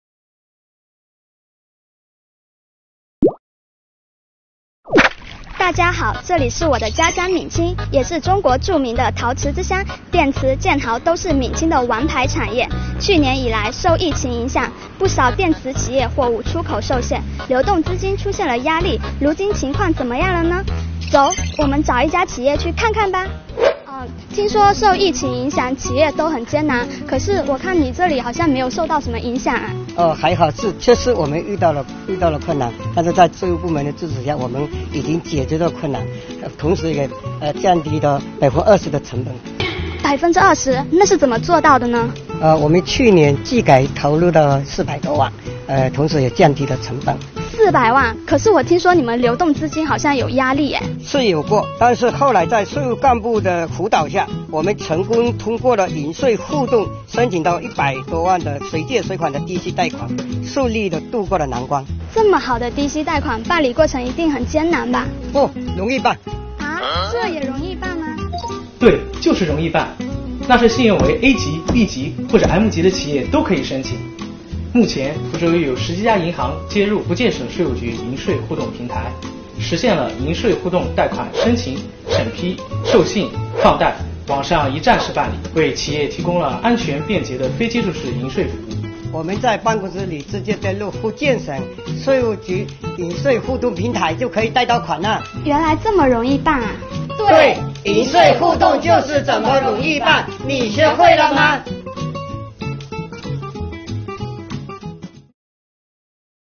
视频以采访形式展开，记者实地采访企业负责人，通过数据对比凸显税务部门助力企业发展的实效，作品真实接地气，让纳税人更好地了解税务部门的办税新举措。